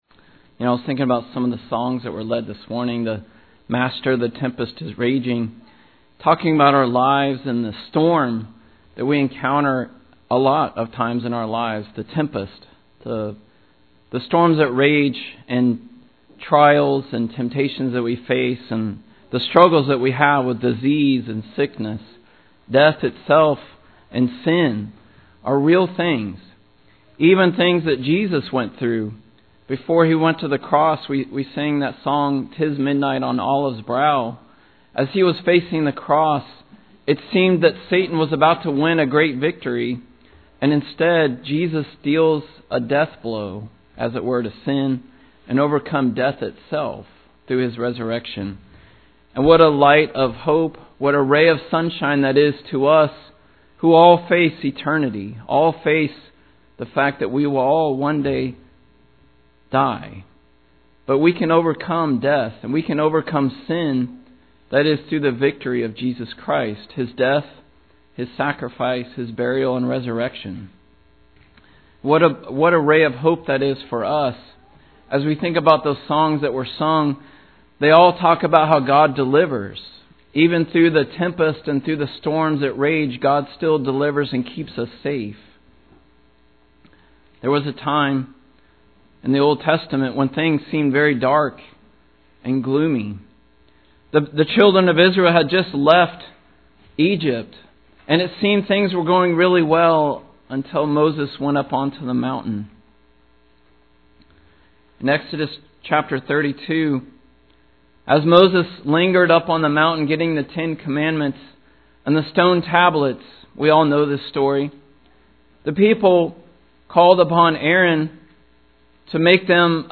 Lesson Recording